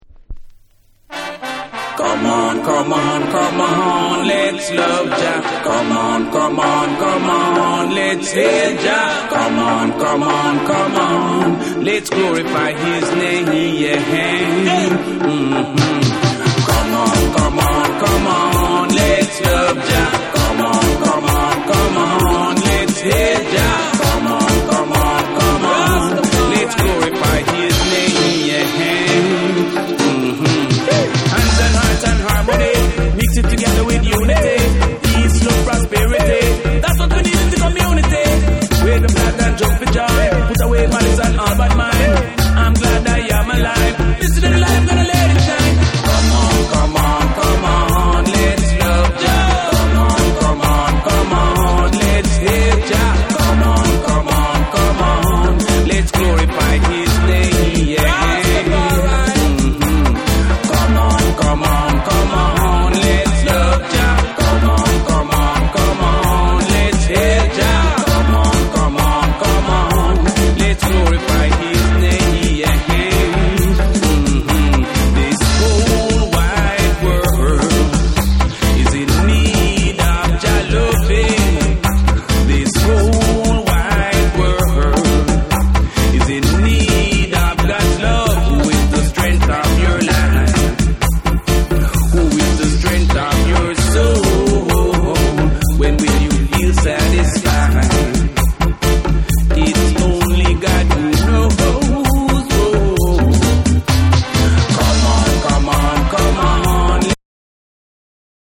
パーカッシヴでアクティヴなステッパー・トラックに、ヘヴィー・ウェイトなベースが絡み
REGGAE & DUB